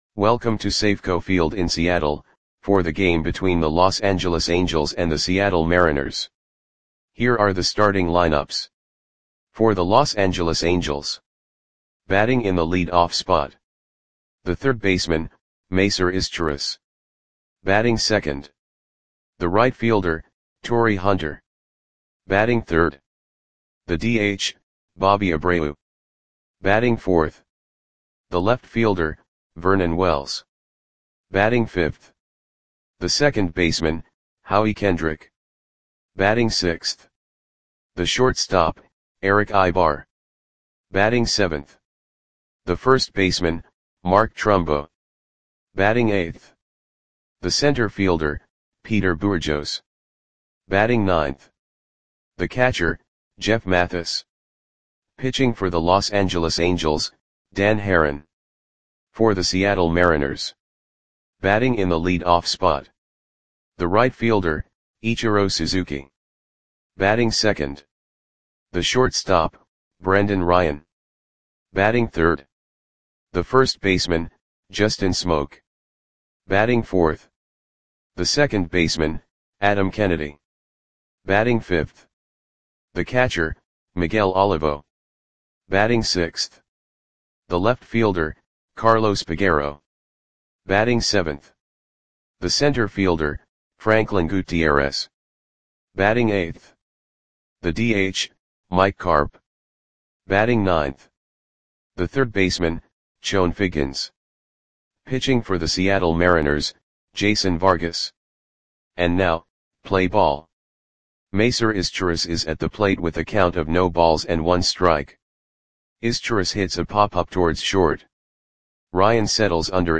Audio Play-by-Play for Seattle Mariners on June 13, 2011
Click the button below to listen to the audio play-by-play.